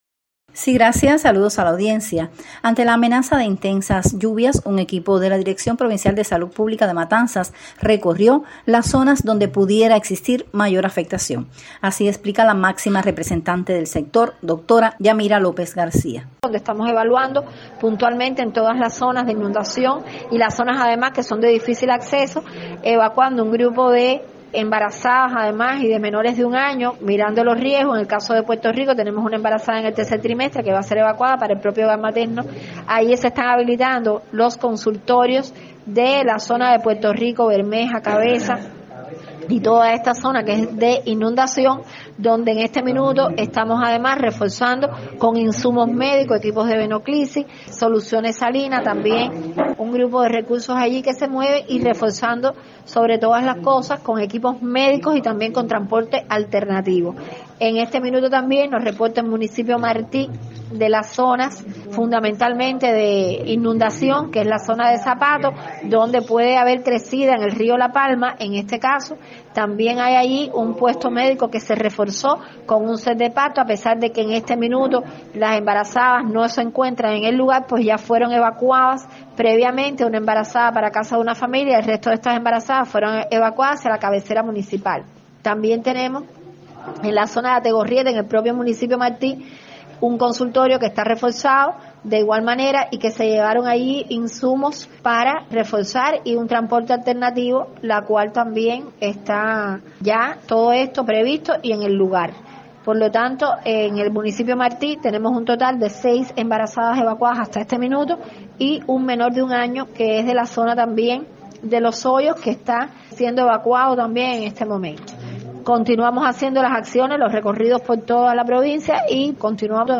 Así explica la máxima representante del sector, doctora Yamira López García.